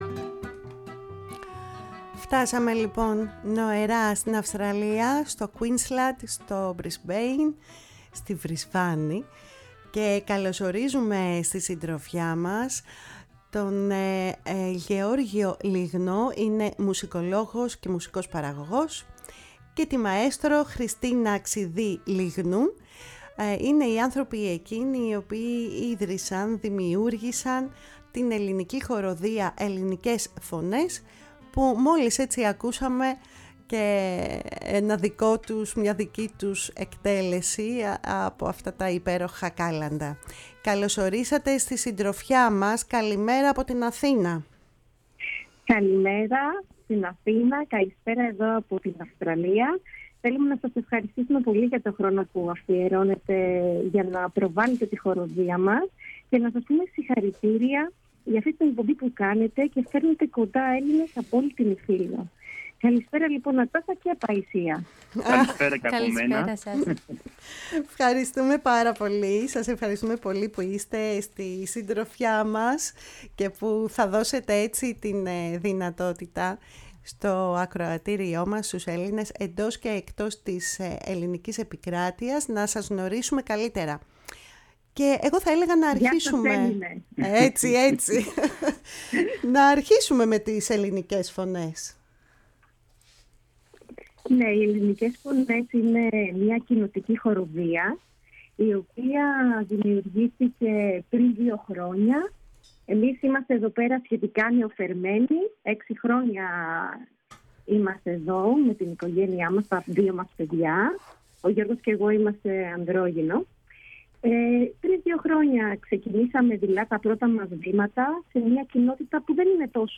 μίλησαν στη “Φωνή της Ελλάδας”